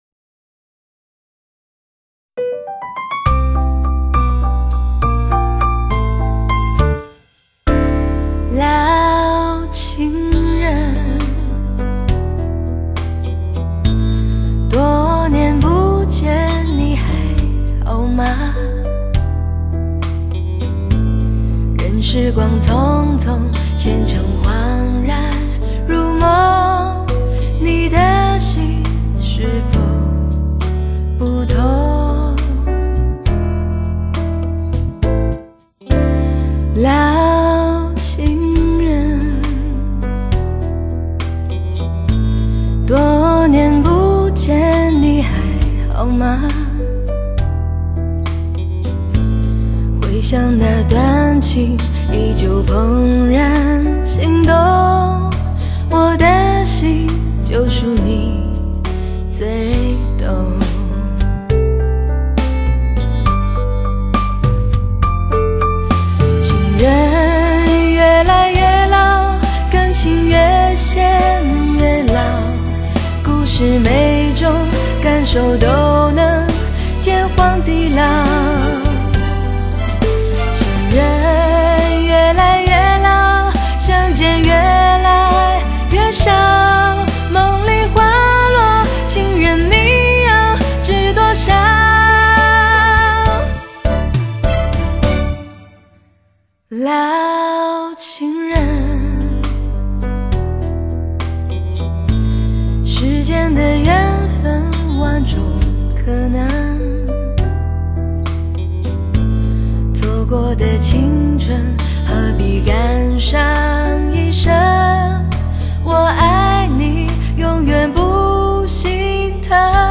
感性极致，人声翘楚。
真正实现高清，全方位360环绕3D立体音效。信噪比高达120dB以上的专业品质，保护爱车音响，支持正版唱片。